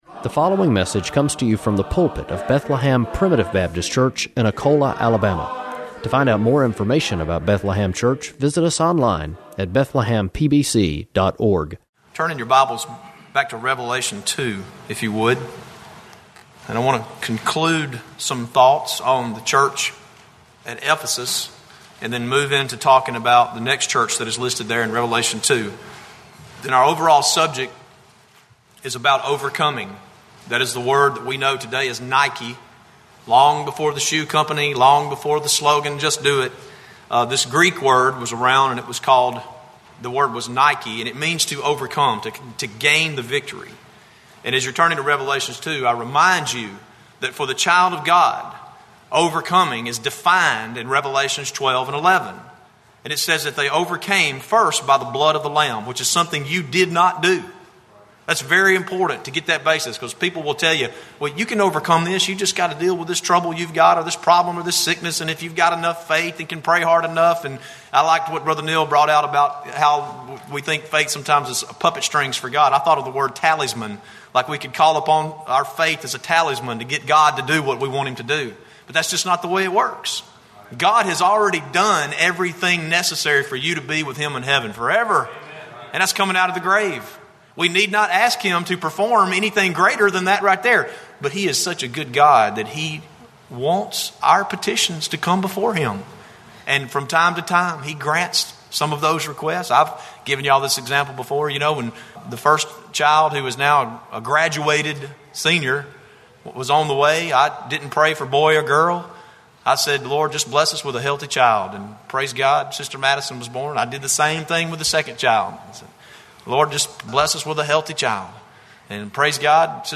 Preached May 28